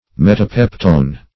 Meaning of metapeptone. metapeptone synonyms, pronunciation, spelling and more from Free Dictionary.
Search Result for " metapeptone" : The Collaborative International Dictionary of English v.0.48: Metapeptone \Met`a*pep"tone\, n. [Pref. meta- + peptone.]